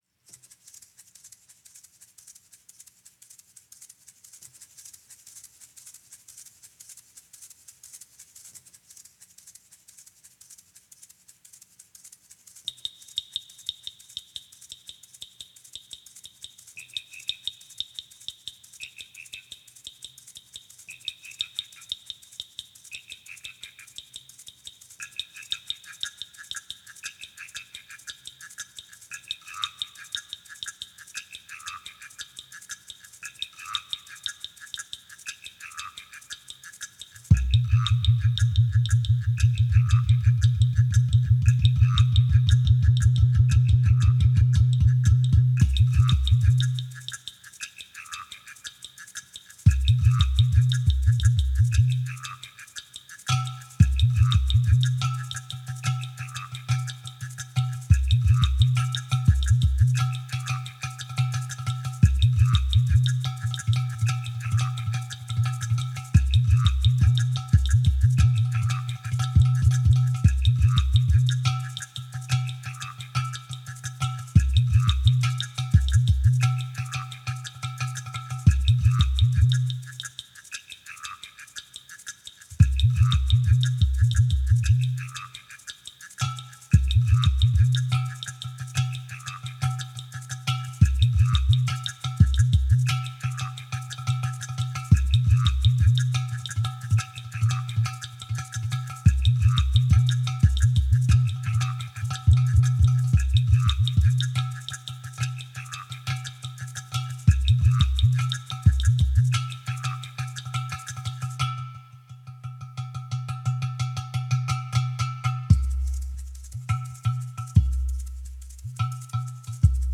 Voicing: Hand Percussion and Electronics